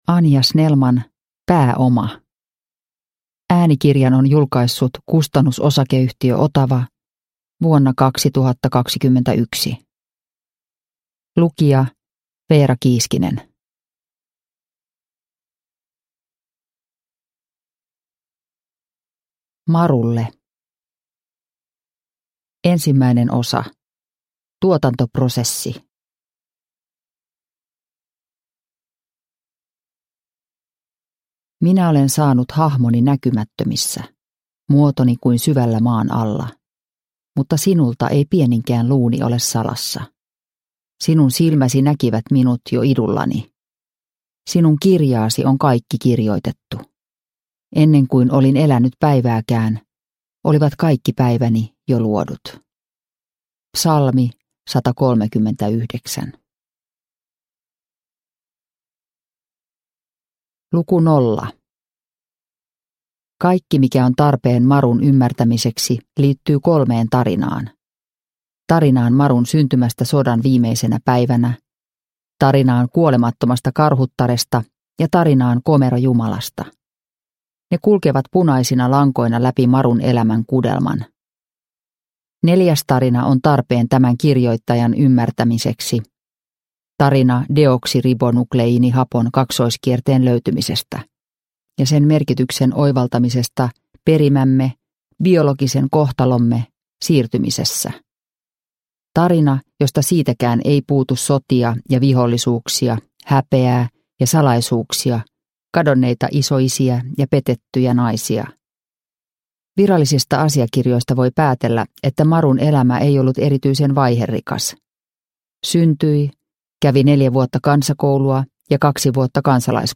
Pääoma – Ljudbok – Laddas ner